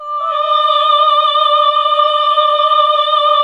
AAH D3 -R.wav